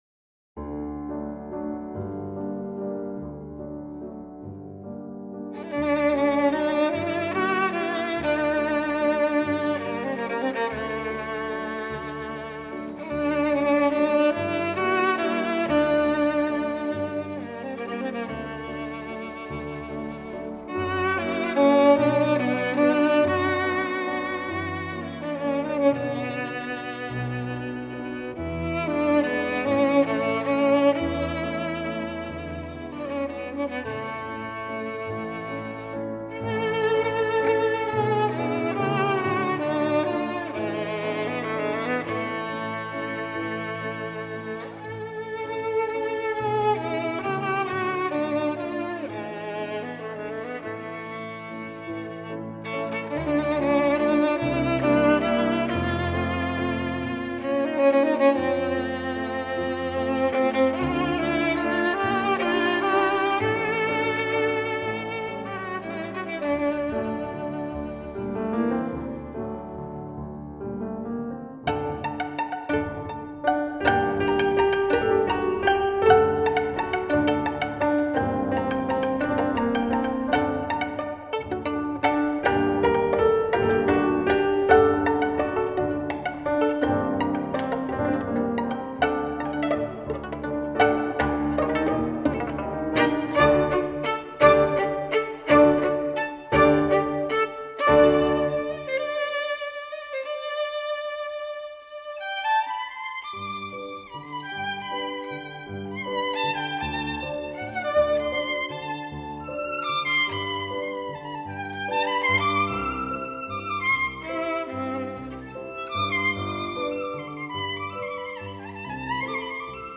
曲调优美，演奏细腻，丝丝入扣
爆发力强，有着惊人的速度和技巧